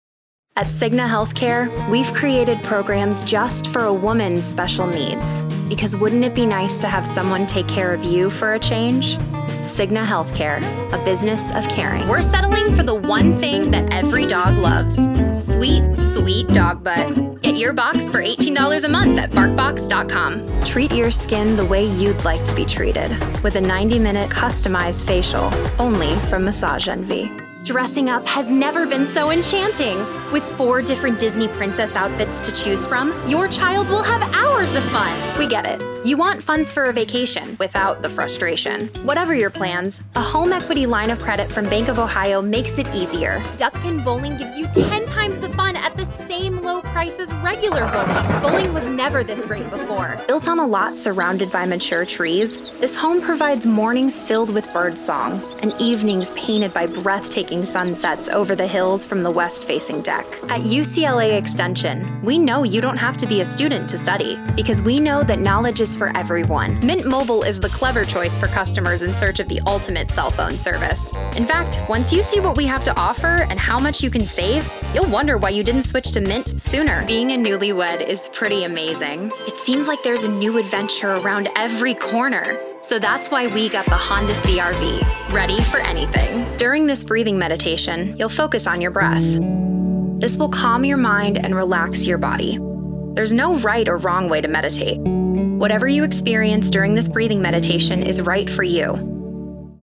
Female Voice Over, Dan Wachs Talent Agency.
Upbeat, Modern, Warm, Conversational.
Commercial